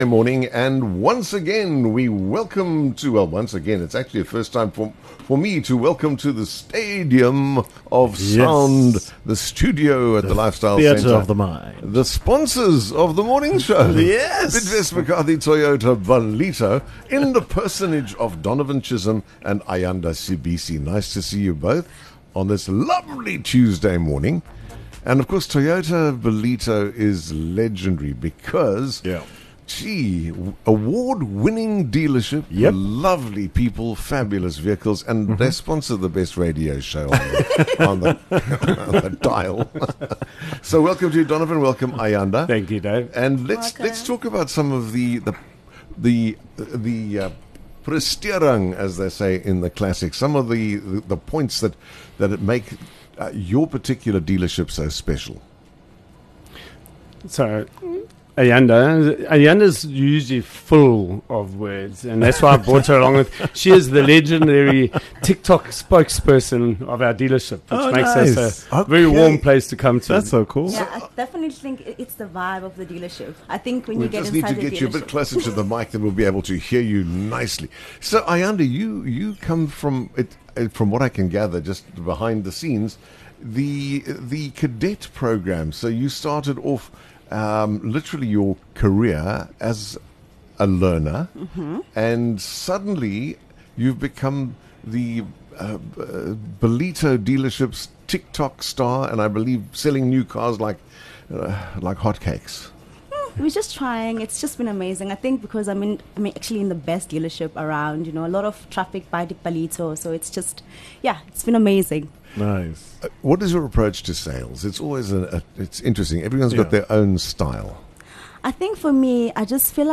8 Apr Driving Success: A Chat with Bidvest McCarthy Toyota Ballito